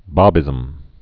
(bäbĭzəm)